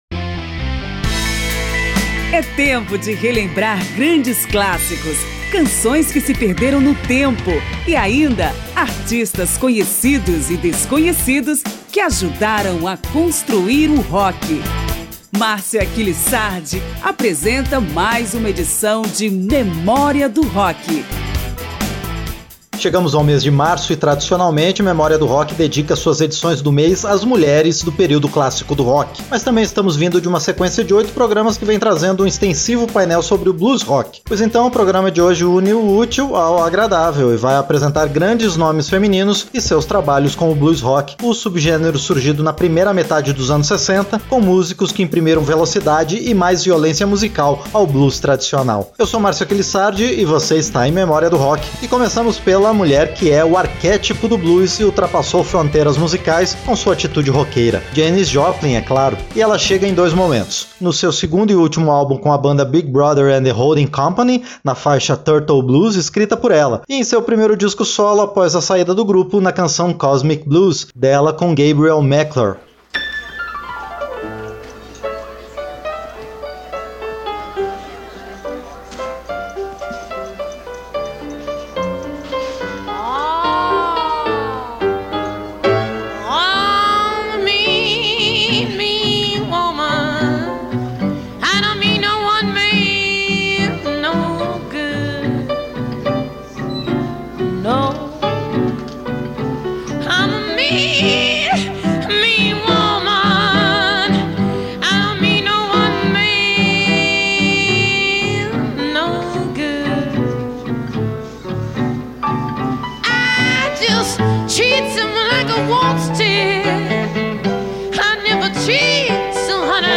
Pesquisa, texto e apresentação